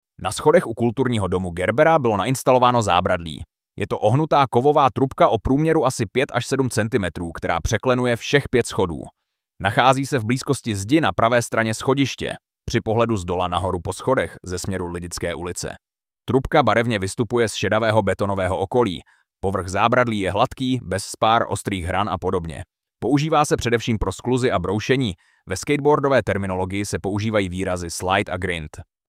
AUDIOPOPIS ZÁBRADLÍ ZE SCHODŮ